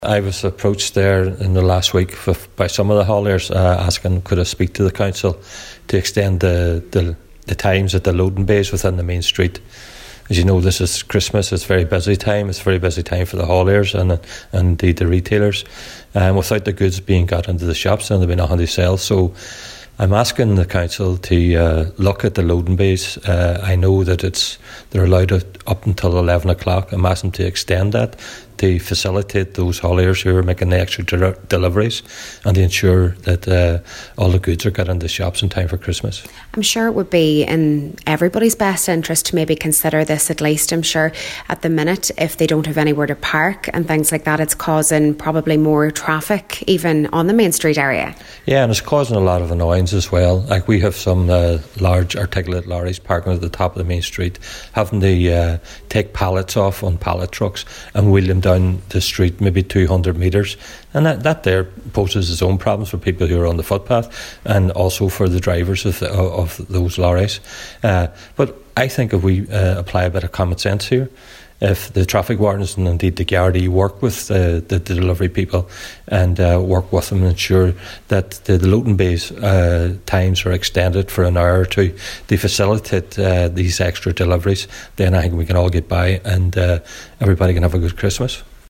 Local Cllr Gerry McMonagle says hauliers are having problems in getting goods to local shops and that has to be addressed: